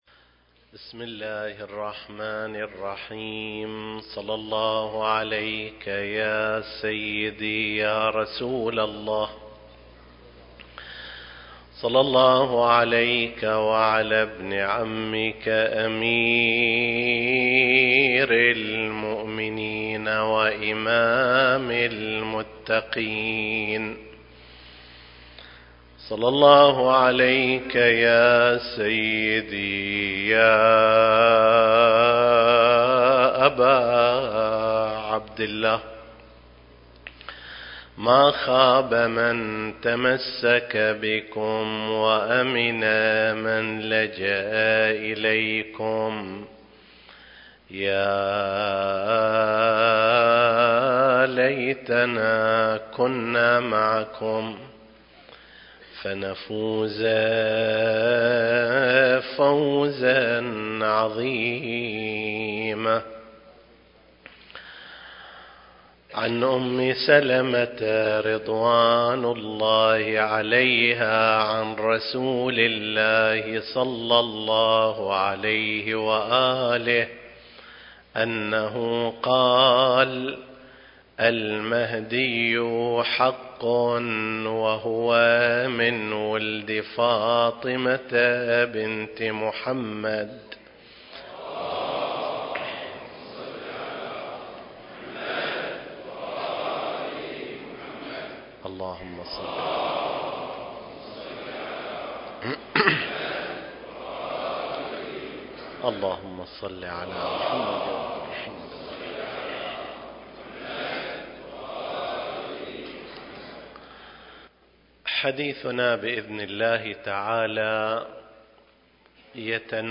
المكان: جامع الرسول الأعظم (صلّى الله عليه وآله وسلم) - صفوى التاريخ: 1437 للهجرة